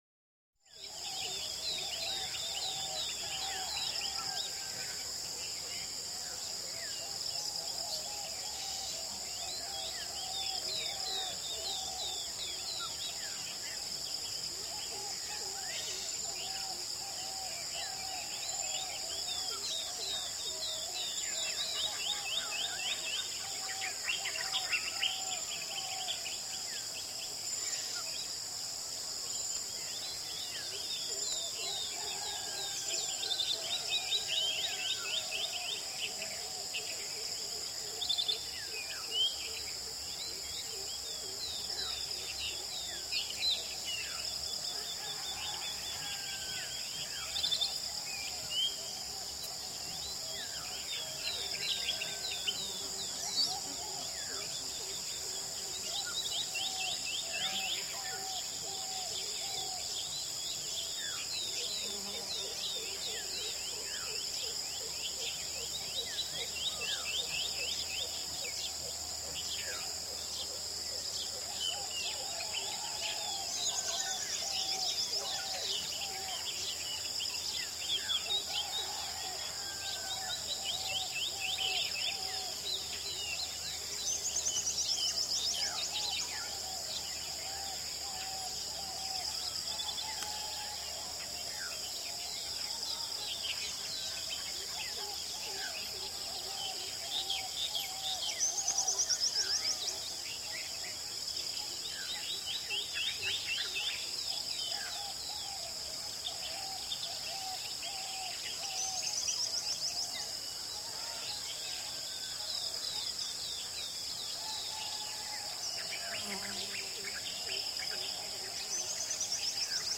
Звуки рассвета
На этой странице собраны звуки рассвета — нежные трели птиц, шелест листвы и другие утренние мотивы.
Утренний рассвет в тропиках Шри-Ланки